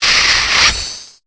Cri de Scalproie dans Pokémon Épée et Bouclier.